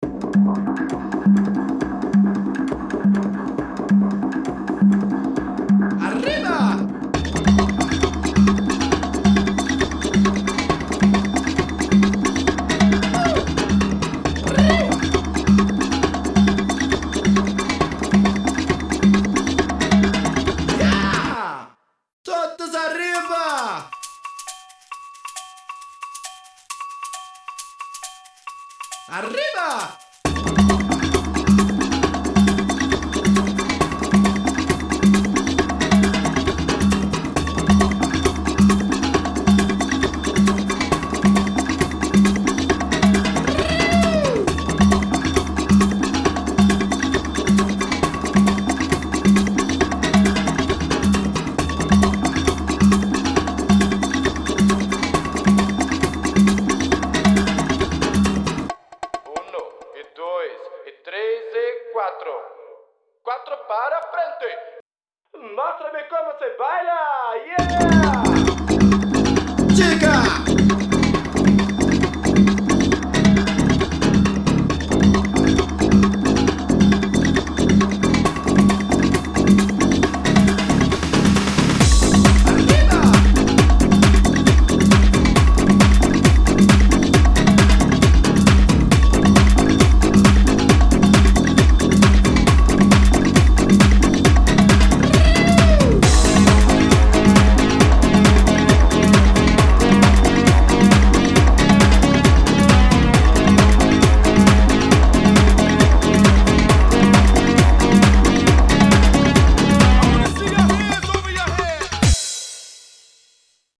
Не законченные миксы из моего архива